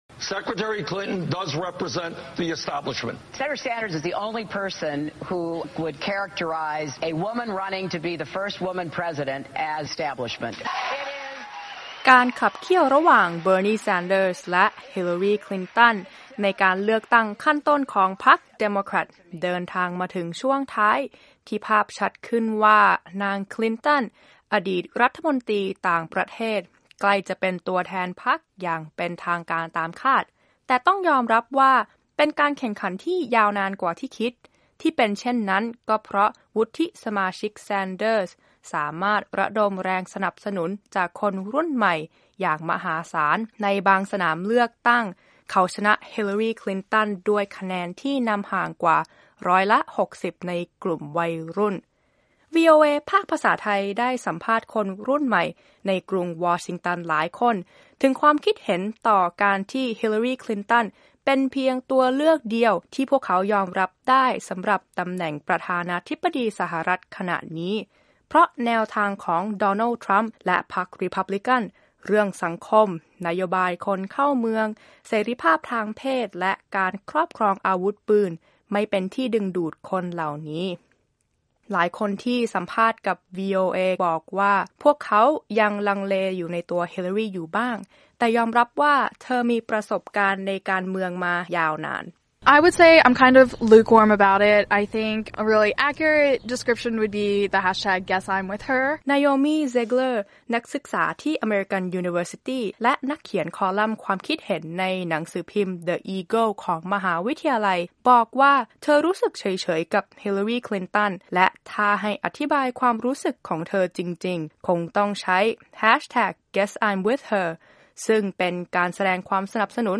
Election Interview
วีโอเอภาคภาษาไทย ได้สัมภาษณ์คนรุ่นใหม่ในกรุงวอชิงตันหลายคน ถึงความคิดเห็นต่อการที่ Hillary Clinton เป็นเพียงตัวเลือกเดียวที่พวกเขายอมรับได้สำหรับตำแหน่งประธานาธิบดีสหรัฐฯ ขณะนี้ เพราะแนวทางของ Donald Trump และพรรครีพับลิกันเรื่องสังคม นโยบายคนเข้าเมือง เสรีภาพทางเพศ และการครอบครองอาวุธปืน ไม่เป็นที่ดึงดูดคนเหล่านี้